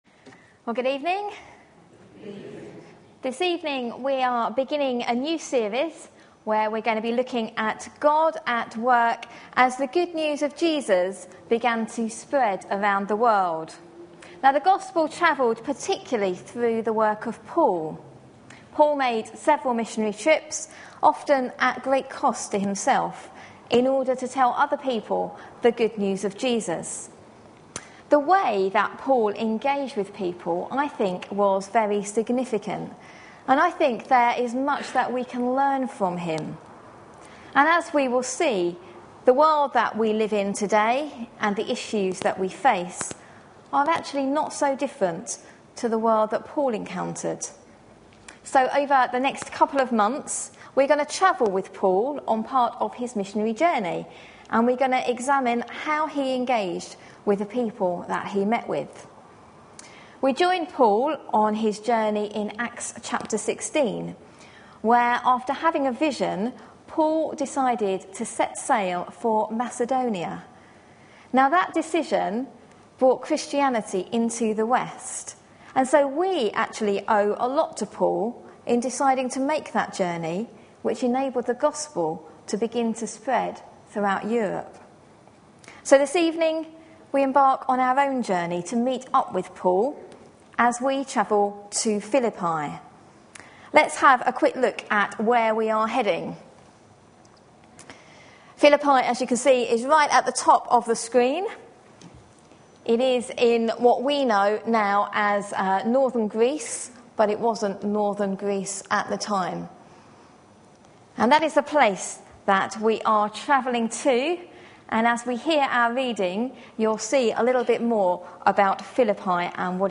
A sermon preached on 22nd May, 2011, as part of our God at Work in..... series.